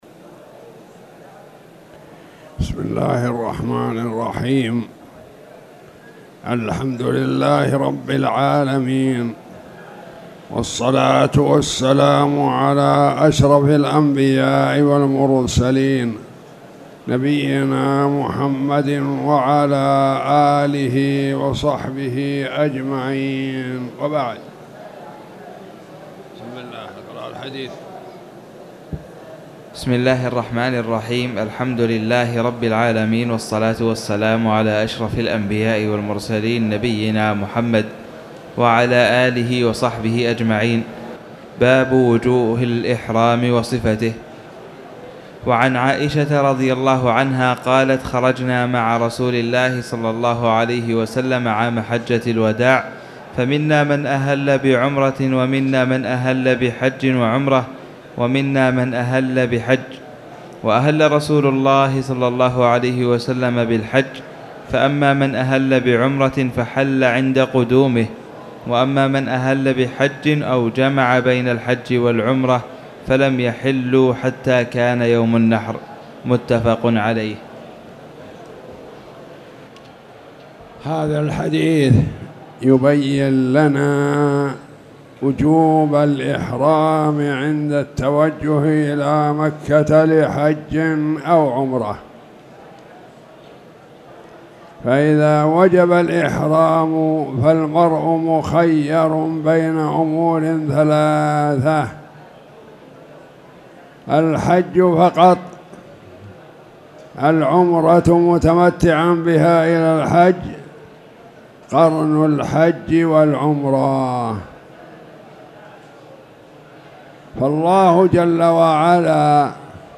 تاريخ النشر ٨ جمادى الأولى ١٤٣٨ هـ المكان: المسجد الحرام الشيخ